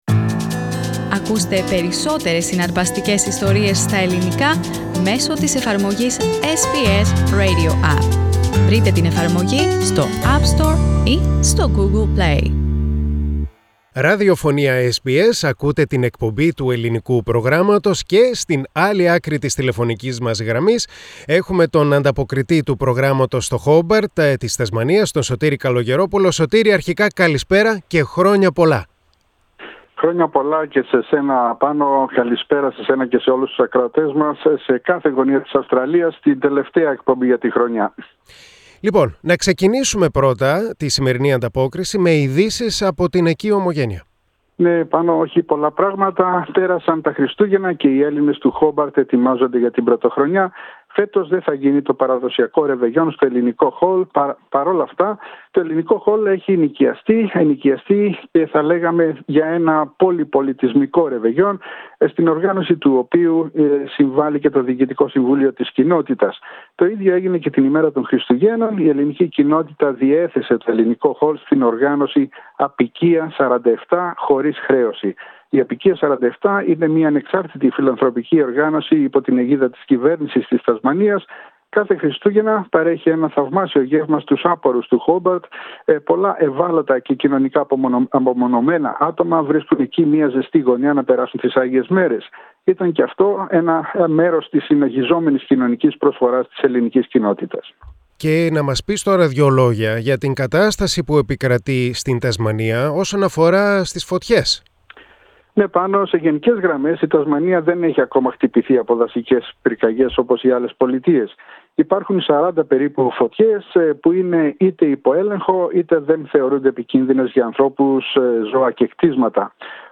Η τελευταία ανταπόκριση του 2019 από το Χόμπερτ της Τασμανίας (31.12.2019).